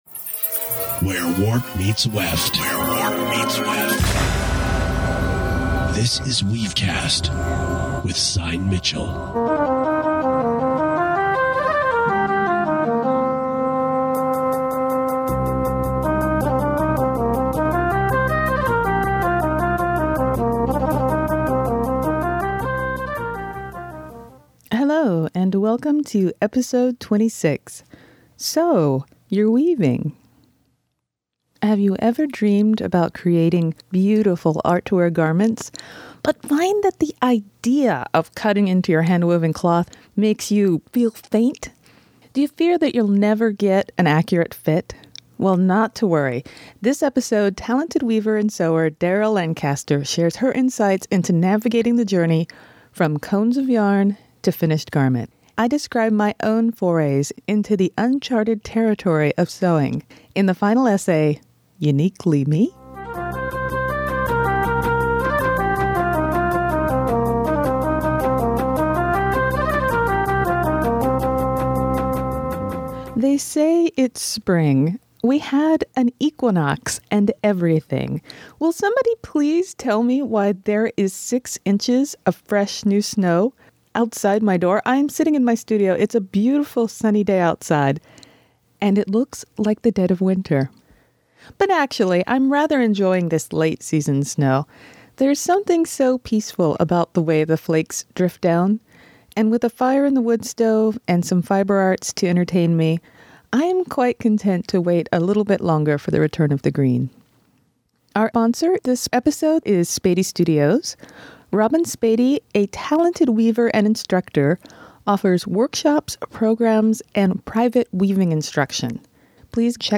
I weave to WeaveCast! A podcast interview